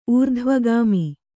♪ ūrdhvagāmi